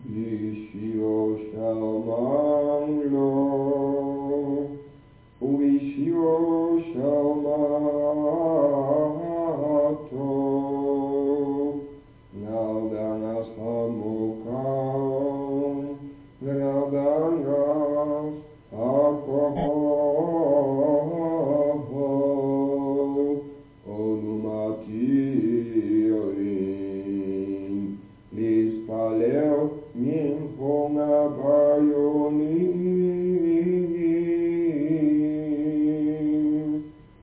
op cassettebandjes.